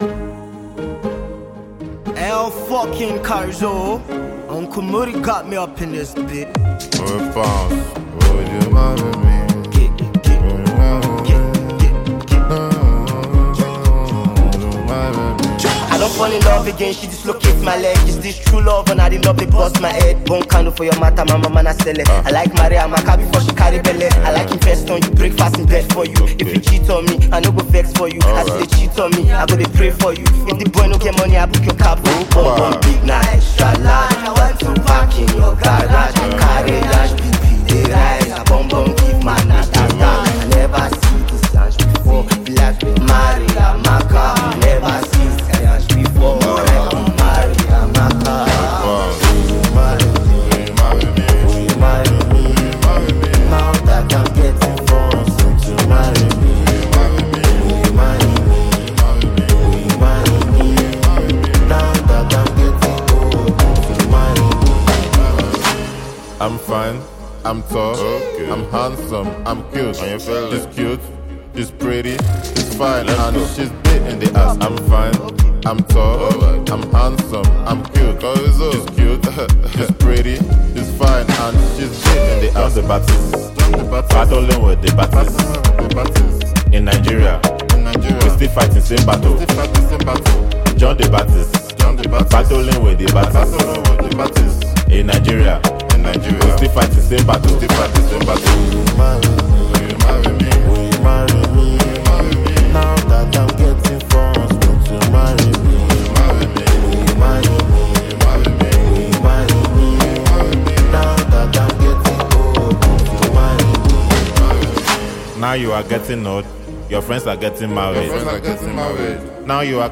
Talented Nigerian Singer